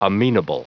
Prononciation du mot amenable en anglais (fichier audio)
Prononciation du mot : amenable